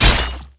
metalmediumsliceflesh3.wav